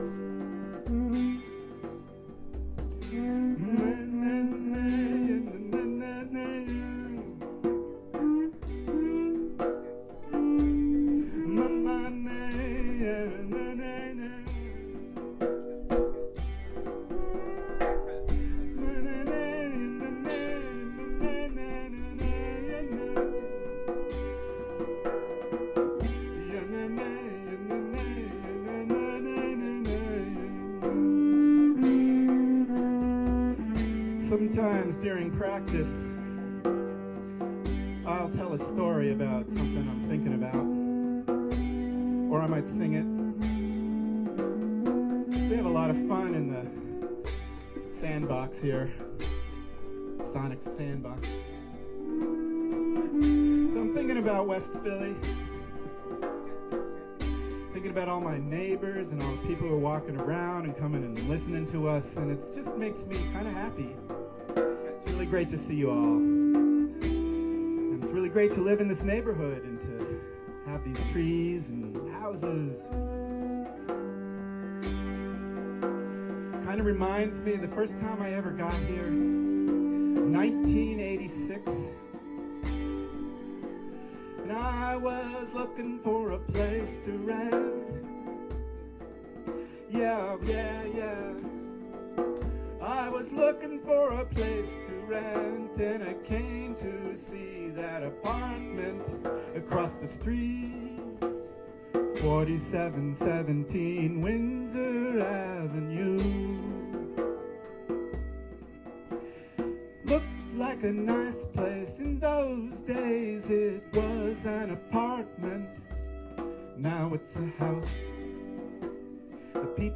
One of the things I like to do sometimes when we improvise, is to sing a story that I make up as I go along.
I sang the story that I am now writing.
I told myself that playing on our porch was just like any other time we get together to jam.